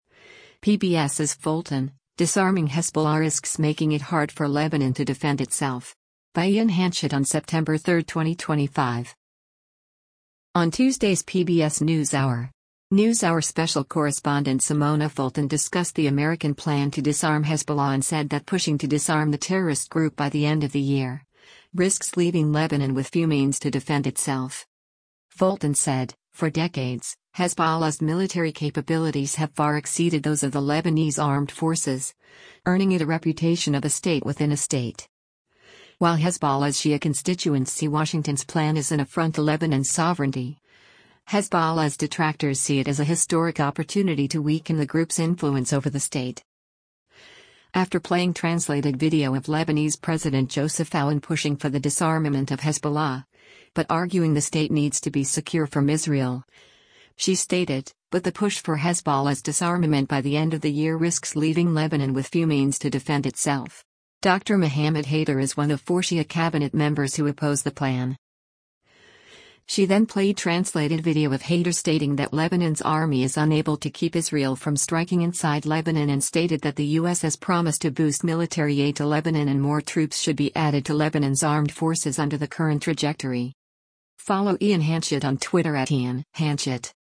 After playing translated video of Lebanese President Joseph Aoun pushing for the disarmament of Hezbollah, but arguing the state needs to be secure from Israel, she stated, “But the push for Hezbollah’s disarmament by the end of the year risks leaving Lebanon with few means to defend itself. Dr. Mohammad Haidar is one of four Shia Cabinet members who oppose the plan.”
She then played translated video of Haidar stating that Lebanon’s Army is unable to keep Israel from striking inside Lebanon and stated that the U.S. has promised to boost military aid to Lebanon and more troops should be added to Lebanon’s armed forces under the current trajectory.